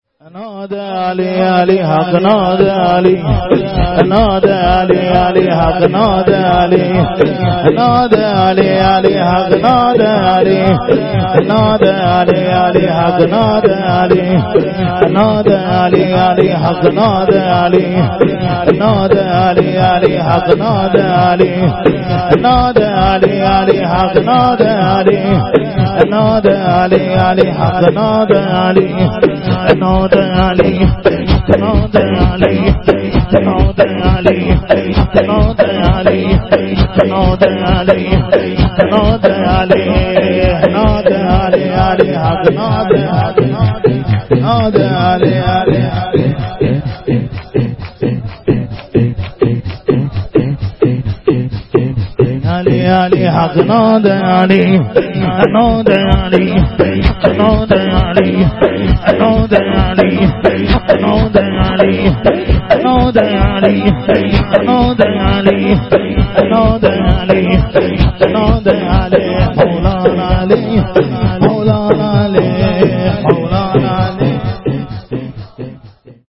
ناد علی(شور....
جلسه هفتگی